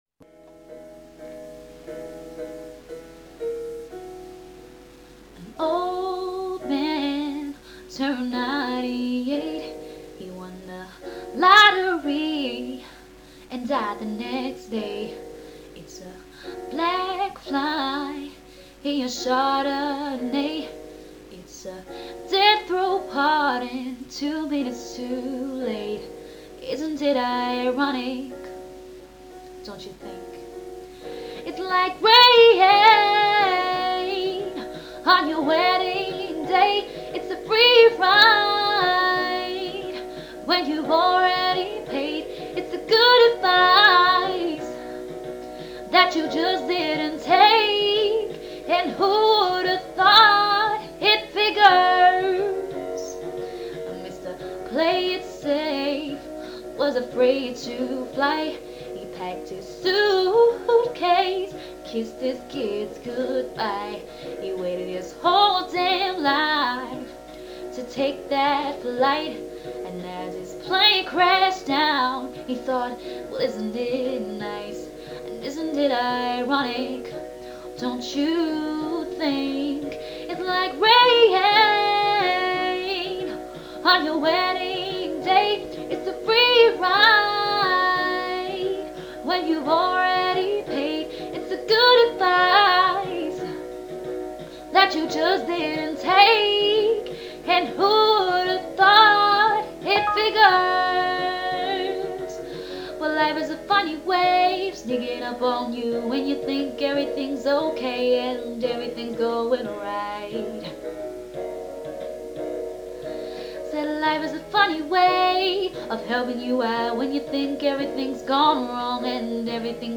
Dutch singer